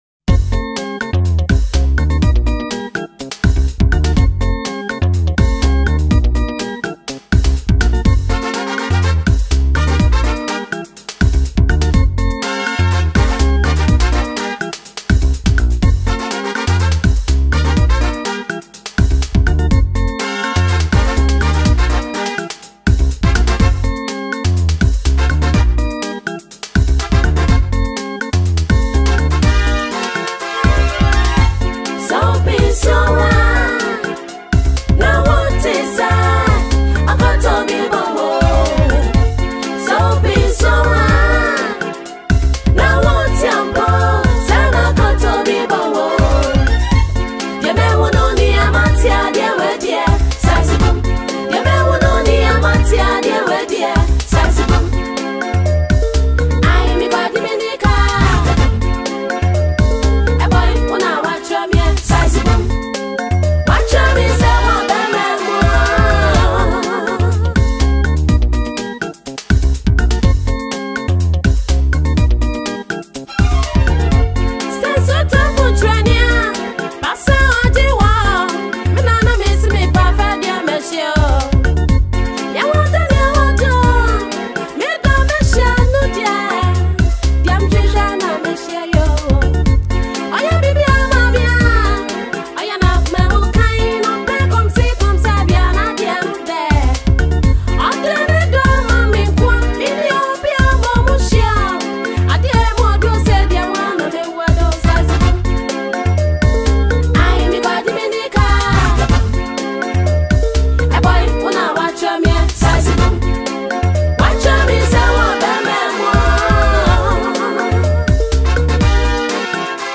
lively and danceable highlife track
all wrapped in a fun and groovy rhythm.